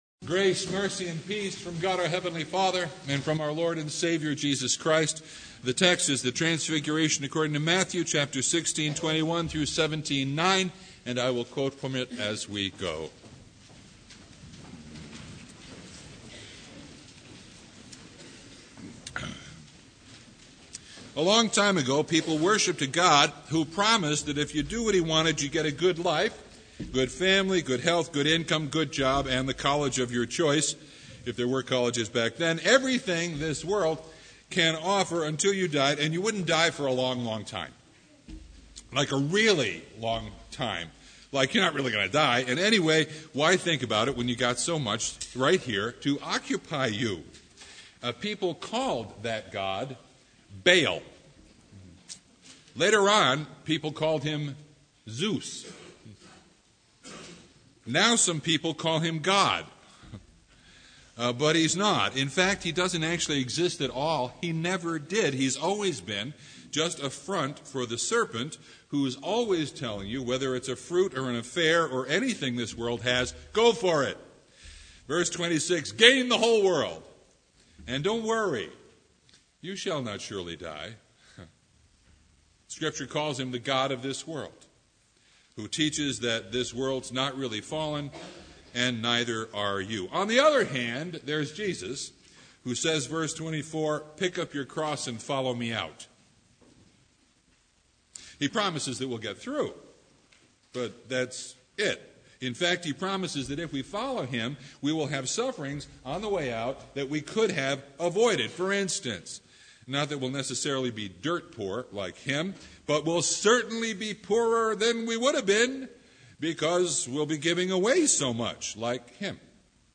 Matthew 17:1-9 Service Type: Sunday The Transfiguration.
Sermon Only